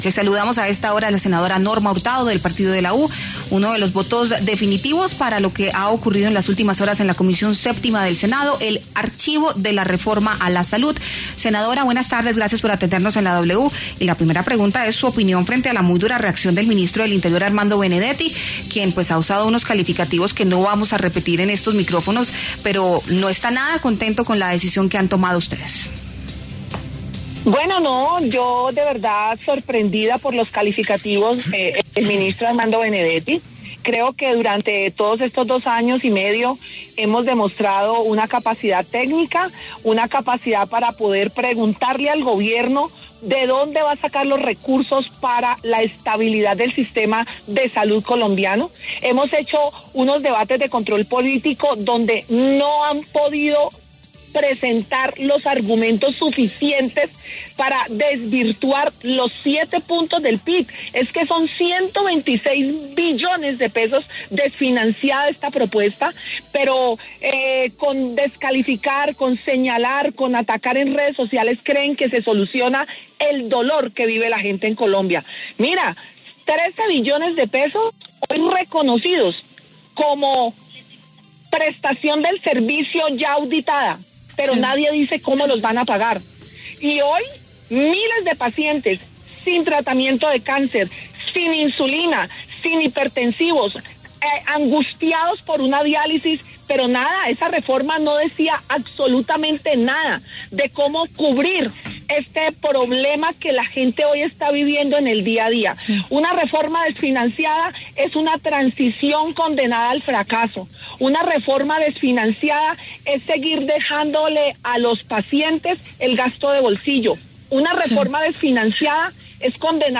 La senadora Norma Hurtado conversó con La W sobre el hundimiento de la reforma a la salud, explicando cuáles fueron sus falencias.